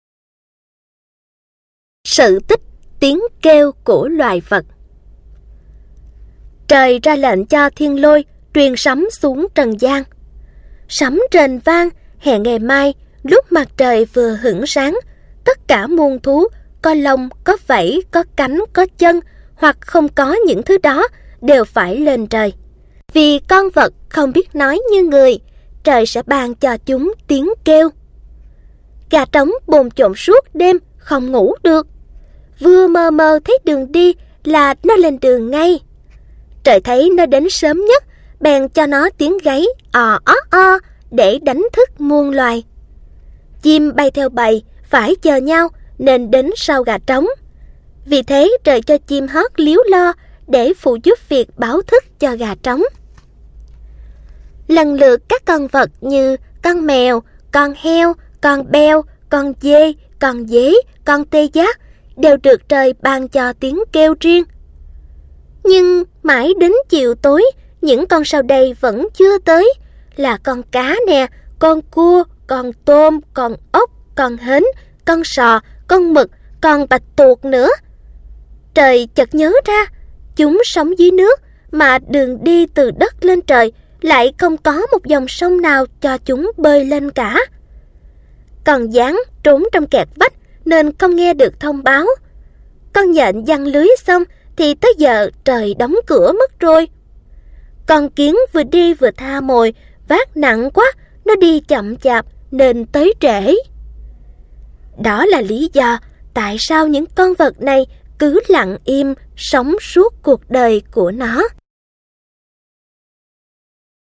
Sách nói | Xóm Đồ Chơi P21